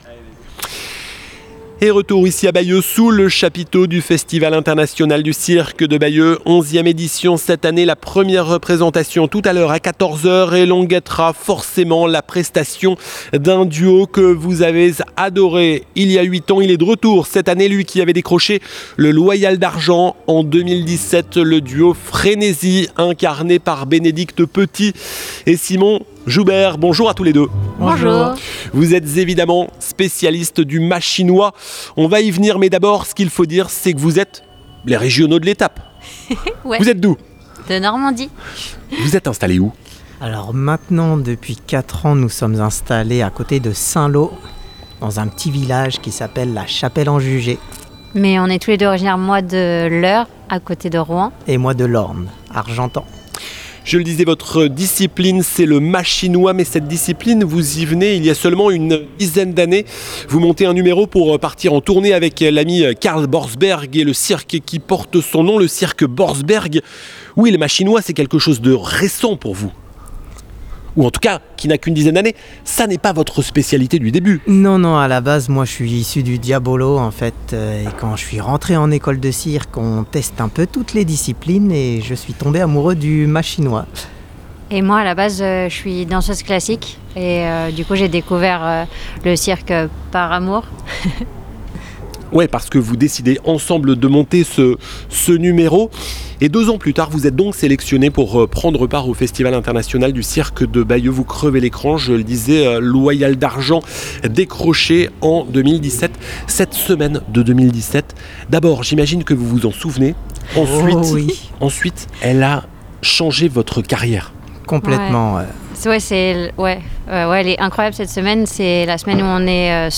RadioVFM au Festival Internation du Cirque de Bayeux - Partie 4